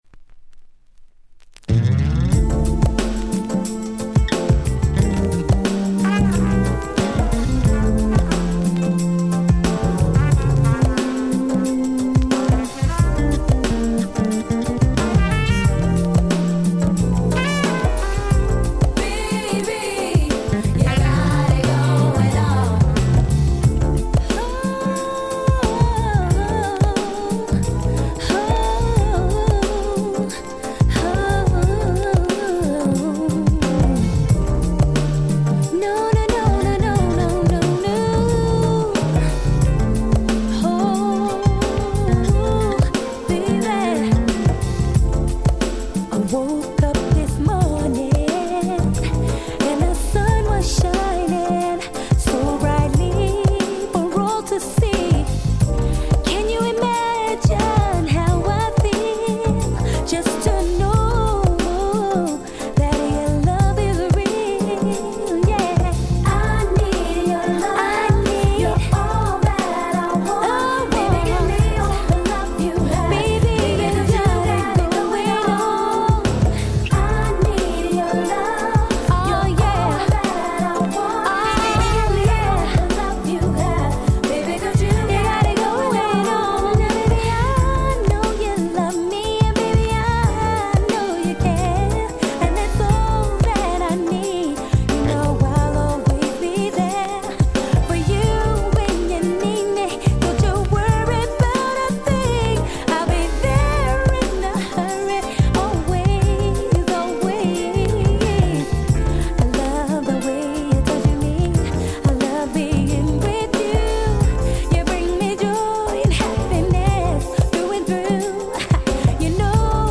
93' Nice R&B LP !!